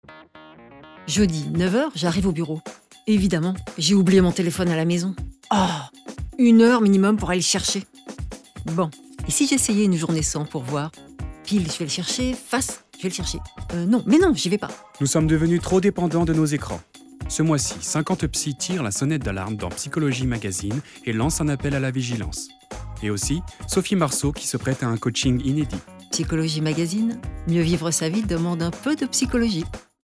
30 - 50 ans - Mezzo-soprano